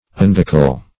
Unguical \Un"guic*al\, a.